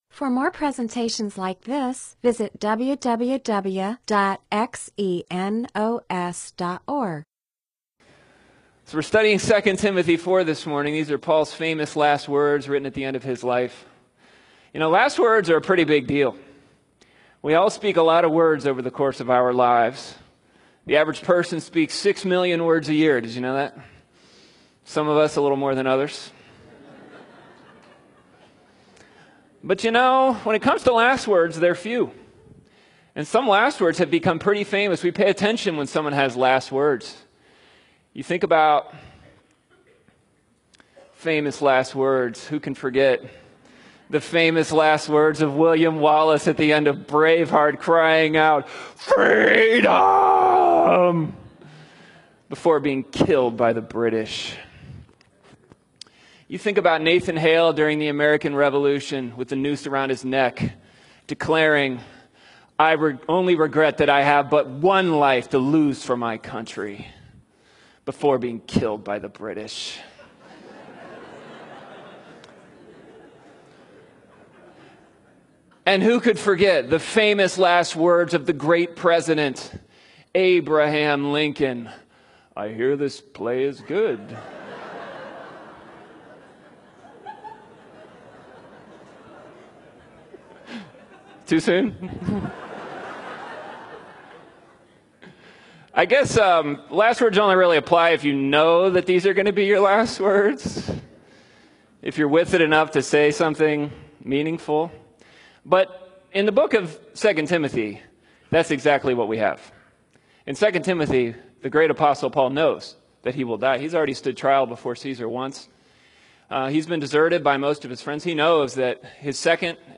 MP4/M4A audio recording of a Bible teaching/sermon/presentation about 2 Timothy 4:1-8.